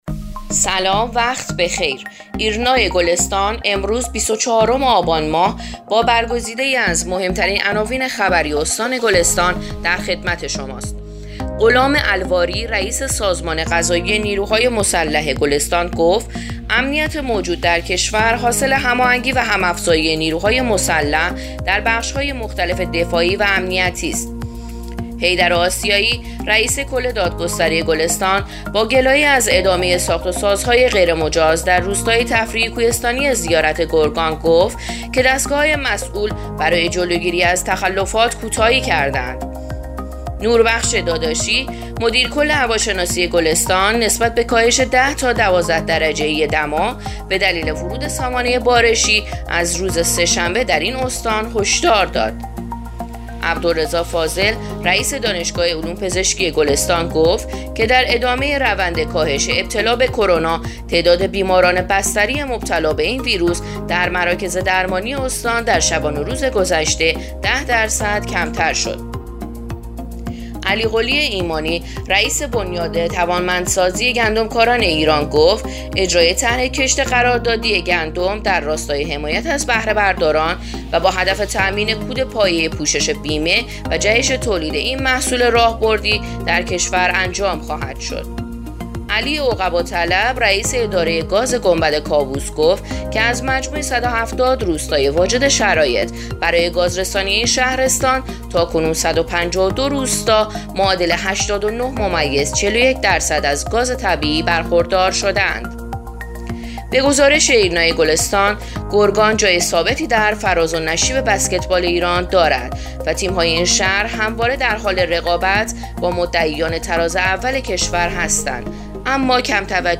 پادکست: اخبار شامگاهی بیست و چهارم آبان ایرنا گلستان